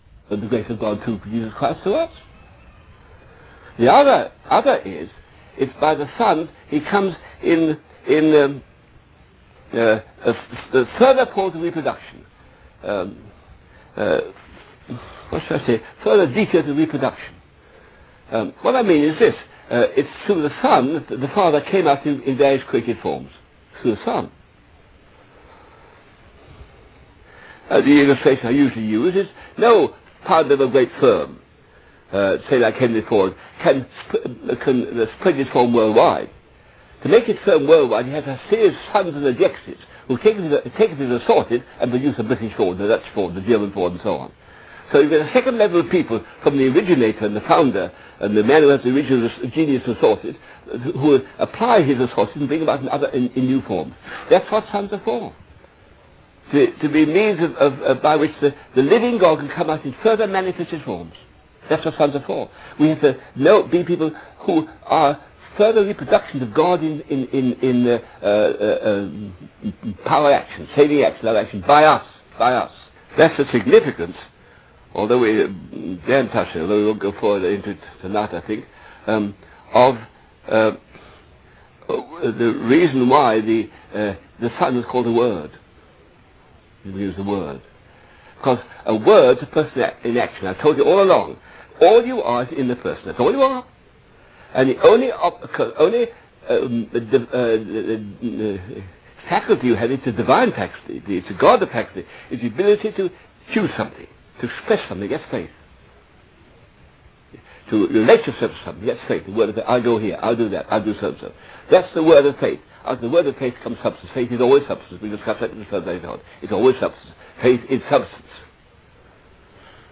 In this sermon, the speaker emphasizes the presence and power of God in our lives.